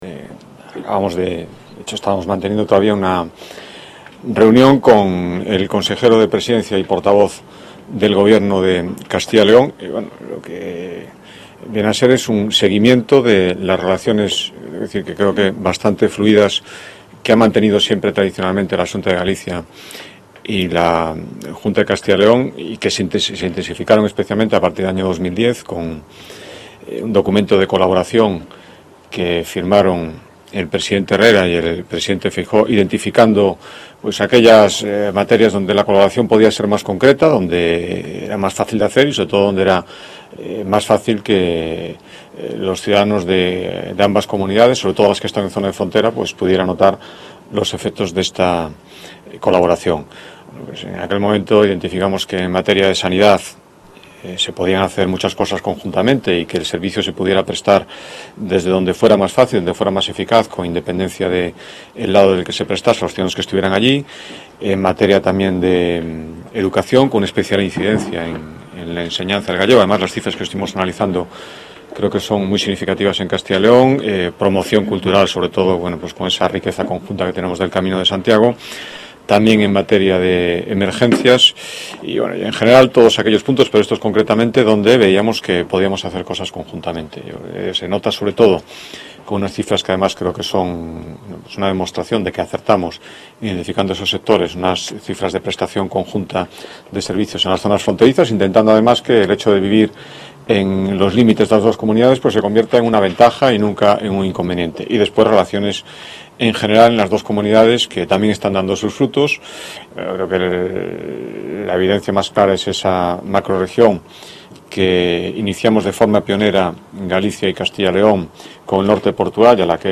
Audio rueda de prensa.